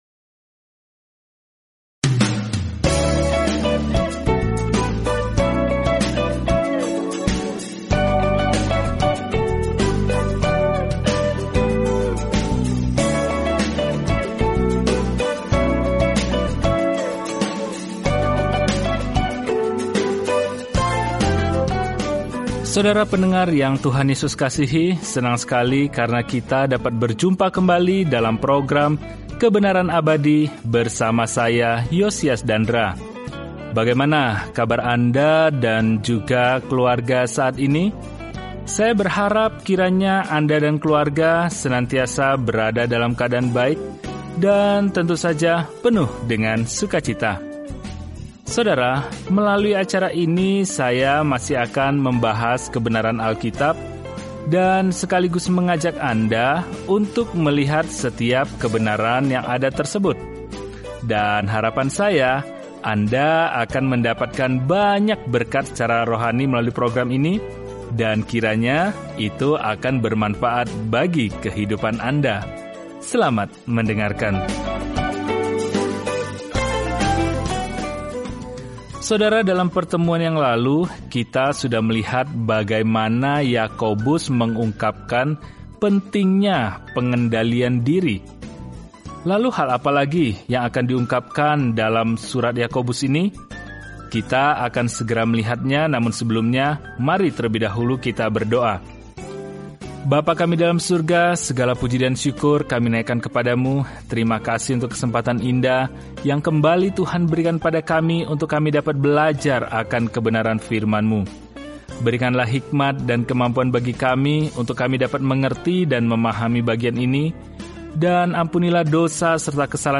Firman Tuhan, Alkitab Yakobus 3:5-18 Hari 11 Mulai Rencana ini Hari 13 Tentang Rencana ini Jika Anda seorang yang percaya kepada Yesus Kristus, maka tindakan Anda harus mencerminkan kehidupan baru Anda; wujudkan iman Anda dalam tindakan. Perjalanan harian melalui Yakobus saat Anda mendengarkan studi audio dan membaca ayat-ayat tertentu dari firman Tuhan.